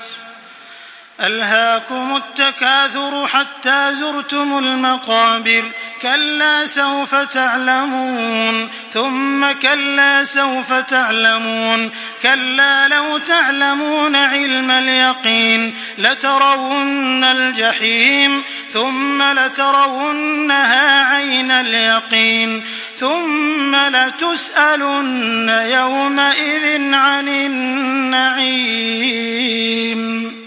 Makkah Taraweeh 1425
Murattal